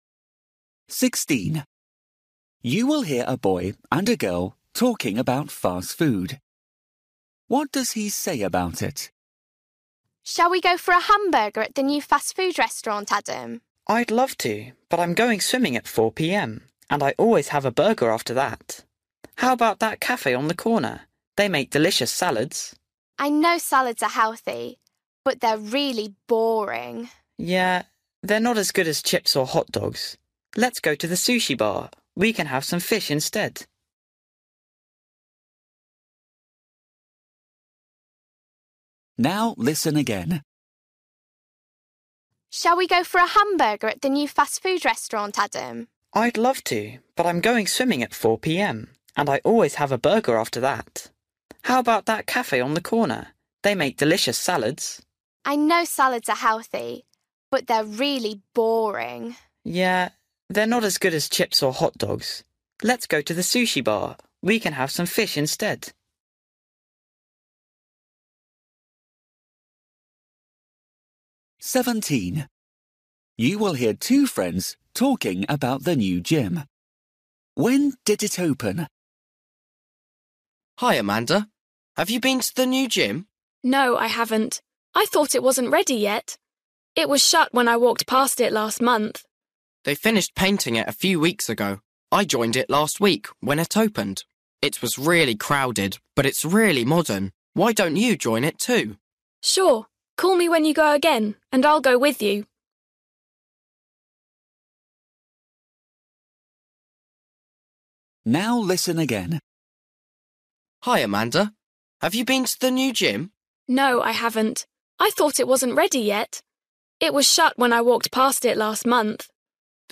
Listening: everyday short conversations
16   You will hear boy and a girl talking about fast food.
17   You will hear two friends talking about the new gym.
19   You will hear a boy and his mother talking about school.